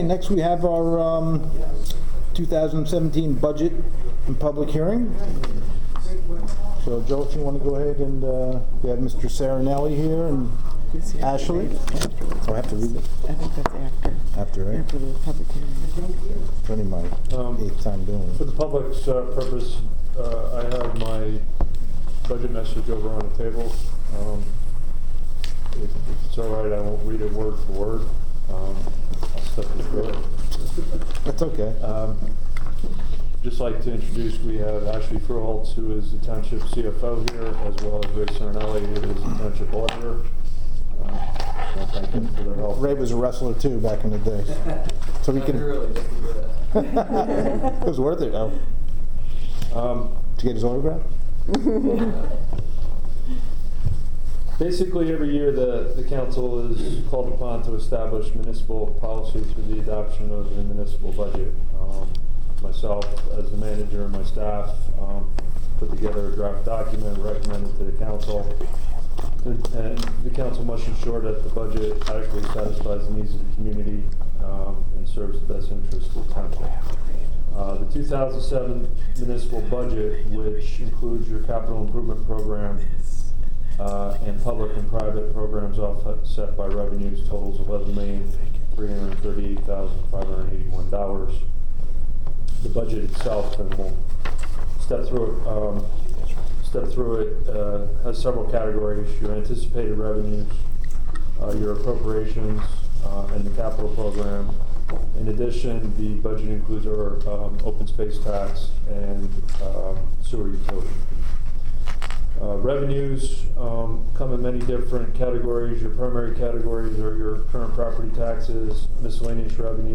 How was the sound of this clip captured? Audio from April 4 Budget Hearing